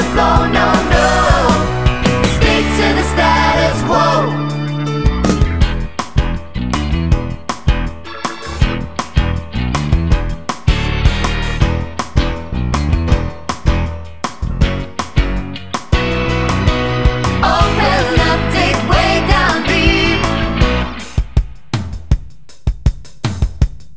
No Backing Vocals Soundtracks 4:28 Buy £1.50